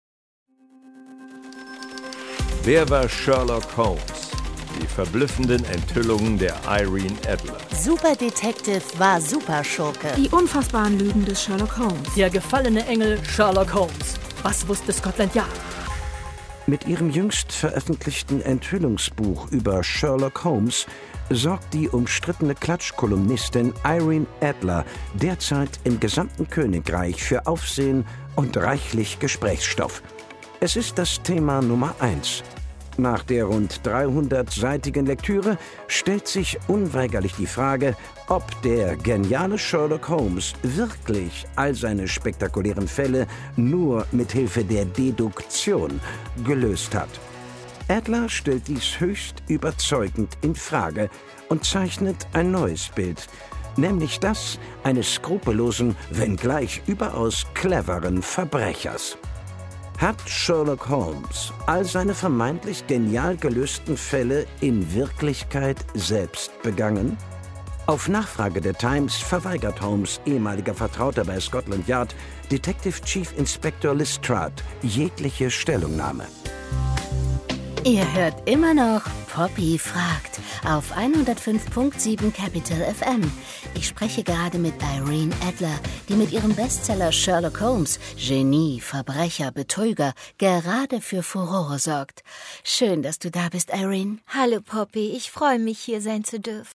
Hörspiele mit Johann von Bülow, Florian Lukas, Fritzi Haberlandt u.v.a. (10 CDs)
Johann von Bülow, Florian Lukas, Fritzi Haberlandt (Sprecher)
»Rasante Schnitte, feiner Witz und eine bis in die kleinsten Rollen exquisite Sprecherriege.« Süddeutsche Zeitung